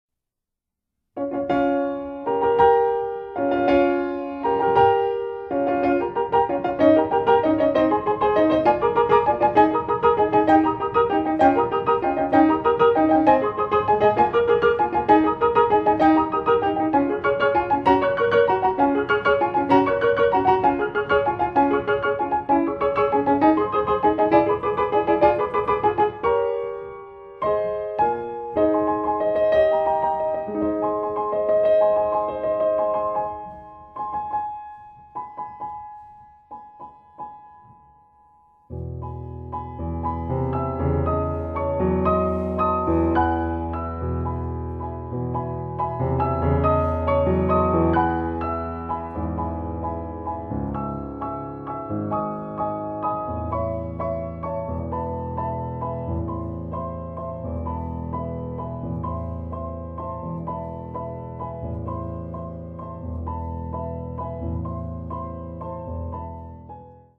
zur vorgegebenen Liedbegleitung zu singen